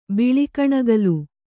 ♪ biḷi kaṇagalu